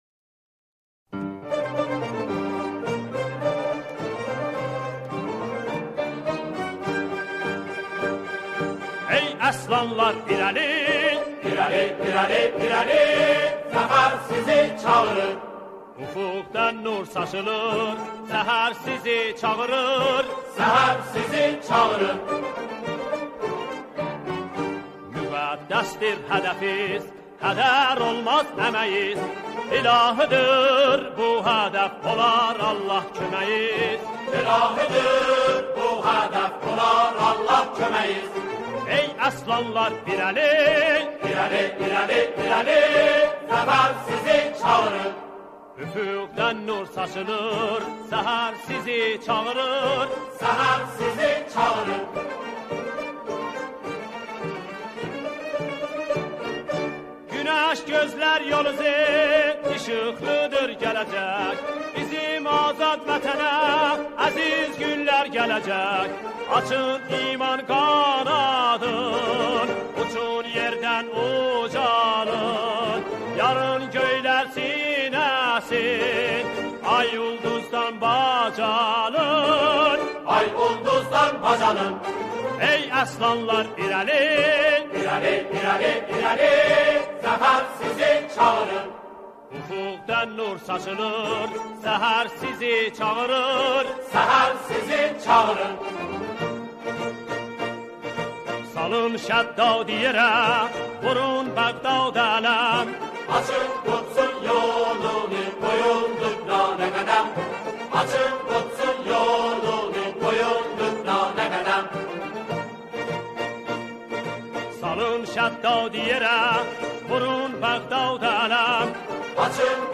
همخوانی شعری به گویش آذری